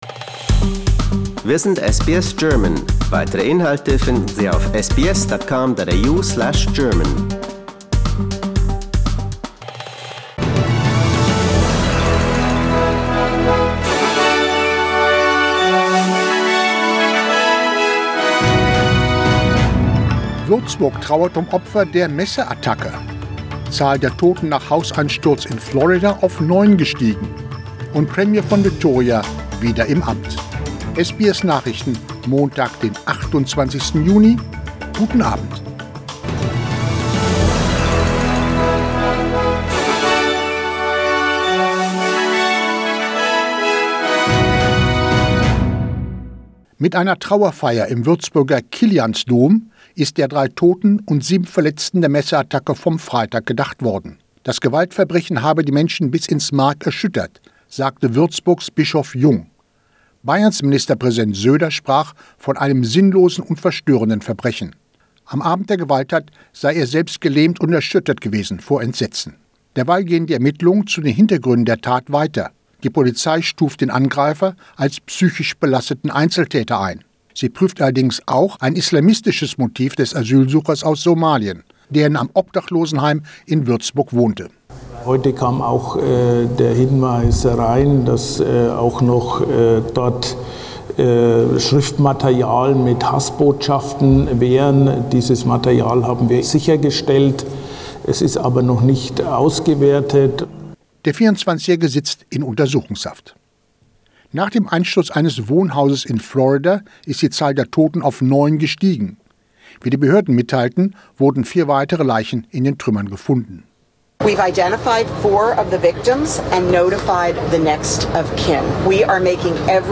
SBS Nachrichten, Montag 28.06.21